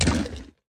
Minecraft Version Minecraft Version snapshot Latest Release | Latest Snapshot snapshot / assets / minecraft / sounds / mob / sniffer / eat2.ogg Compare With Compare With Latest Release | Latest Snapshot
eat2.ogg